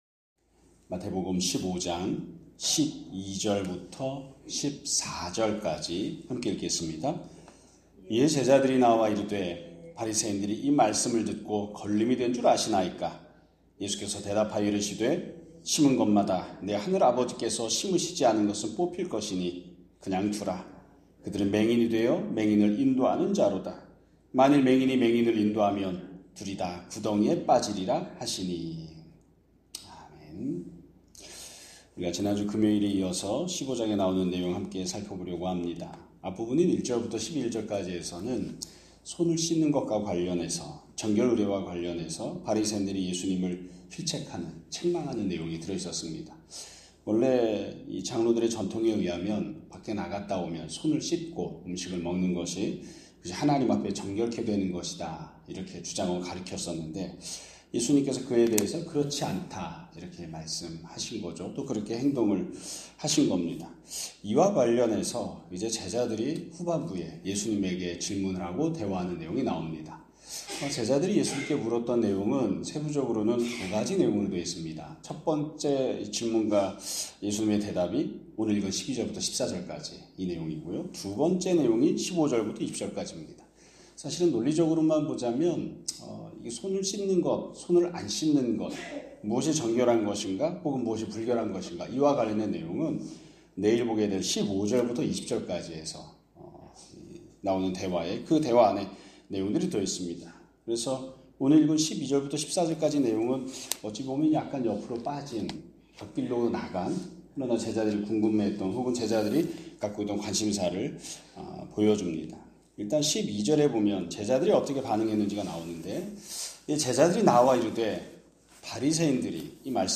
2025년 11월 3일 (월요일) <아침예배> 설교입니다.